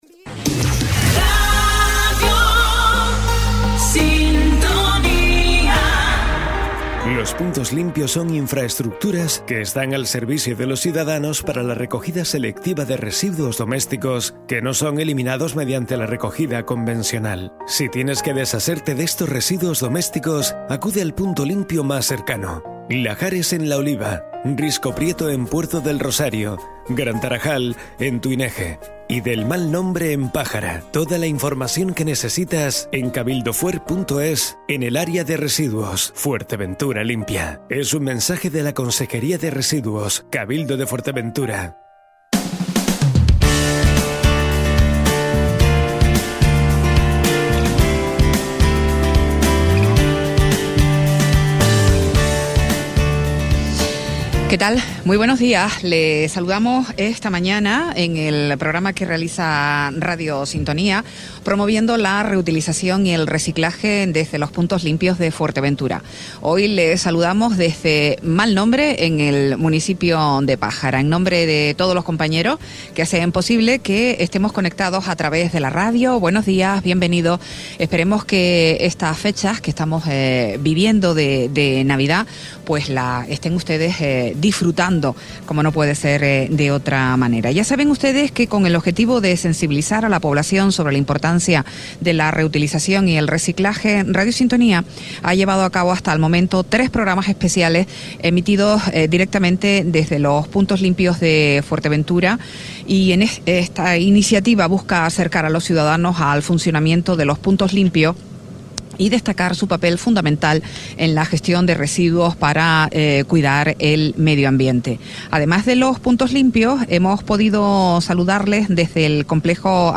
Radio Sintonía se dirige hasta el Punto Limpio de Mal Nombre en su espacio dedicado a la gestión de los residuos en Fuerteventura
Entrevistas